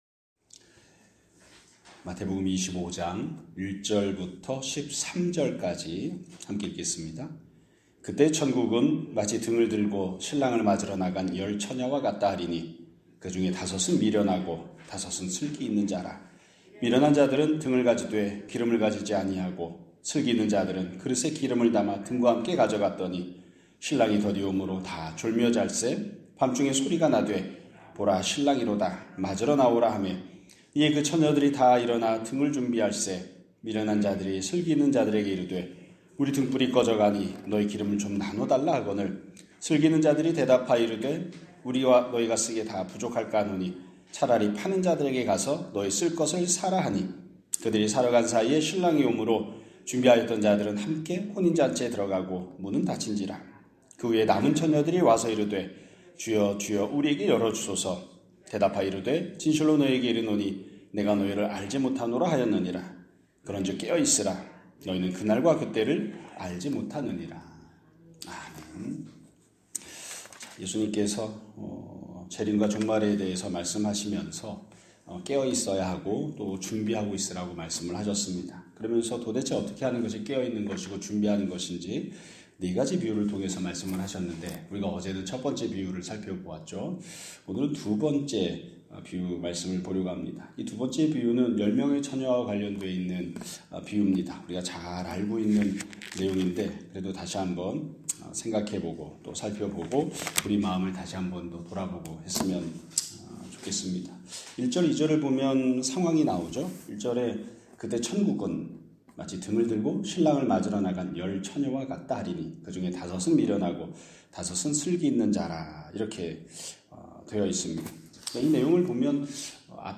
2026년 3월 18일 (수요일) <아침예배> 설교입니다.